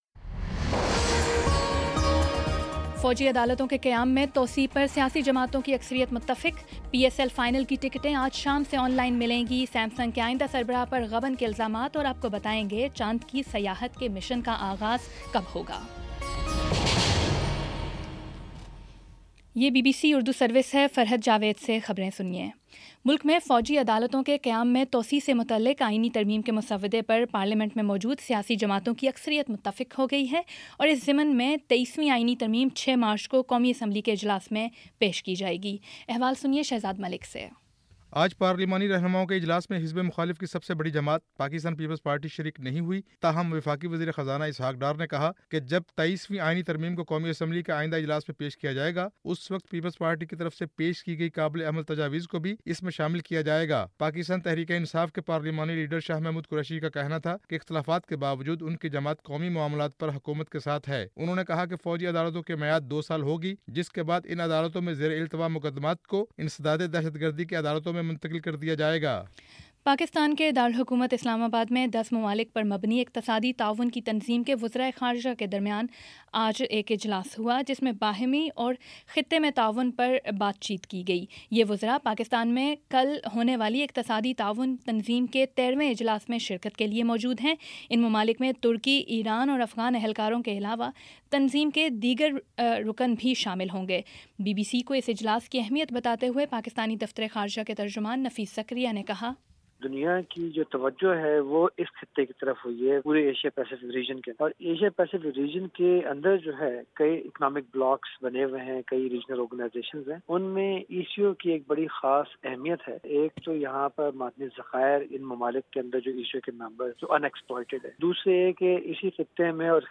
فروری 28 : شام پانچ بجے کا نیوز بُلیٹن